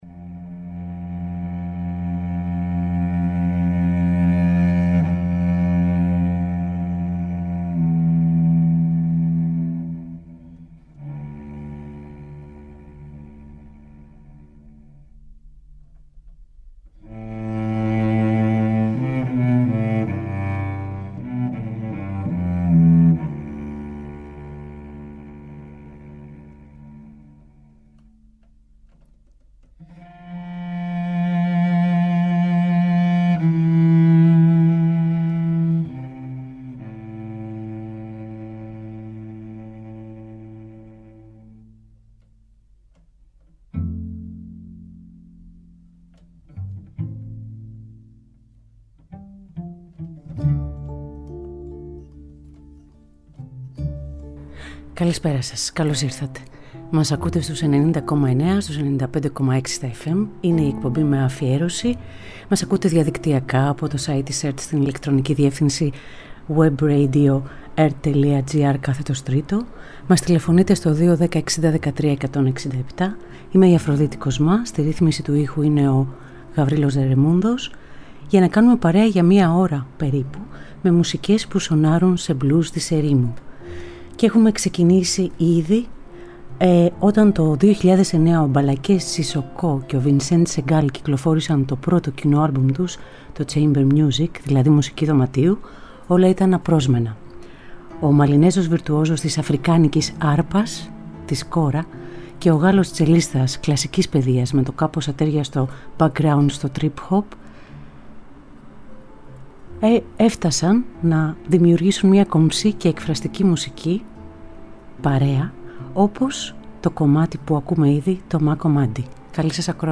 Μπλουζ της ερήμου
Ζωντανά από το στούντιο του Τρίτου Προγράμματος.